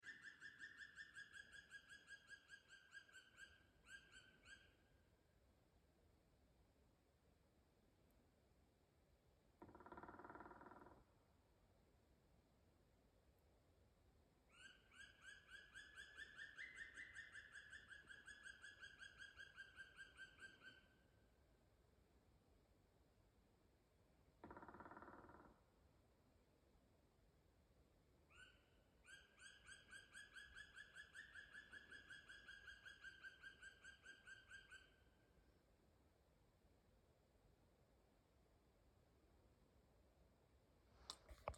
Birds -> Woodpeckers ->
Black Woodpecker, Dryocopus martius
StatusSinging male in breeding season